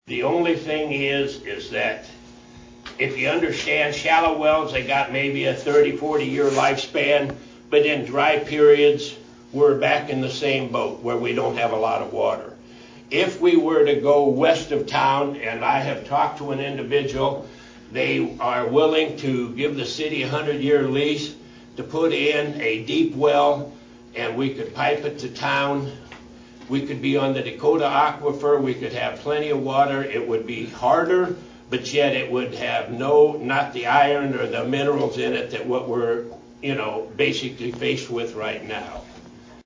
Mayor Phil Przychodizin, speaking at the recent Massena Annual Chamber of Commerce banquet, announced that the city is partnering with Greenfield Municipal Utilities to enhance the water treatment process.